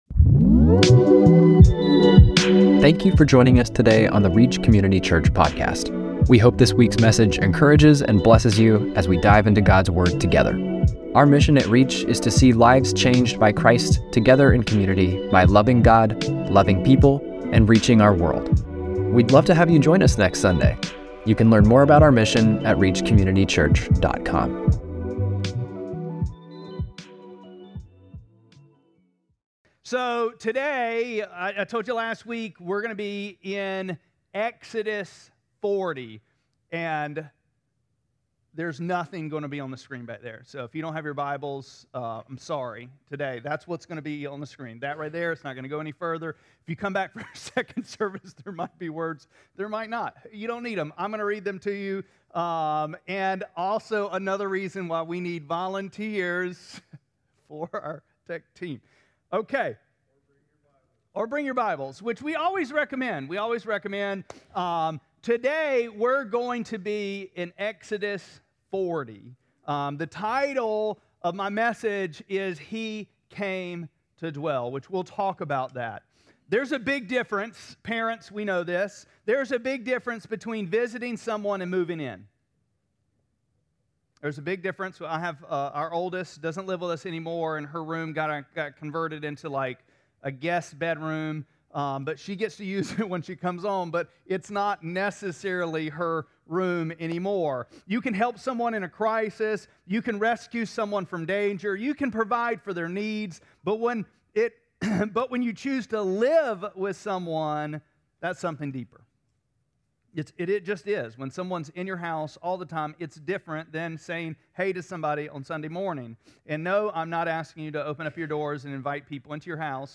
2-15-26-Sermon.mp3